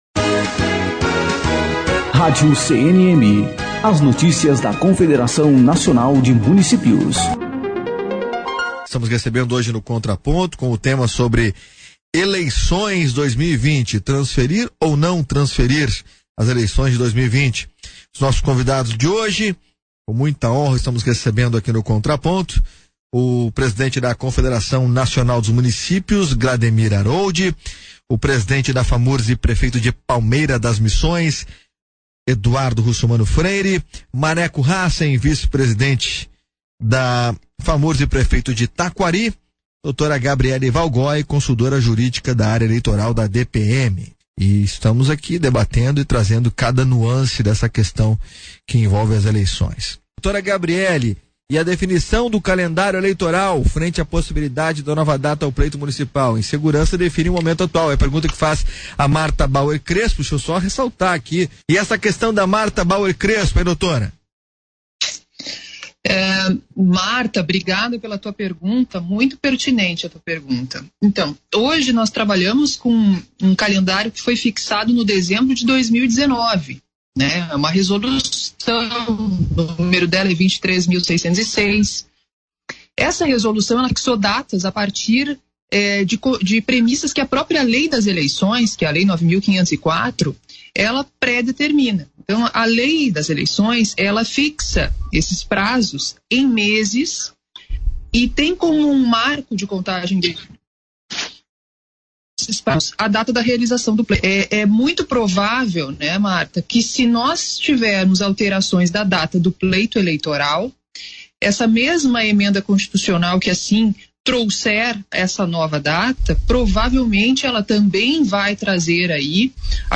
Entrevista - Glademir Aroldi - Eleições | Rádio Guaíba FM 101,3 - Porto Alegre(RS) - Programa Esfera Pública
Entrevista---Glademir-Aroldi---Eleies-RDIO-GUABA-FM-1013---RS--PORTO-ALEGRE--RS.mp3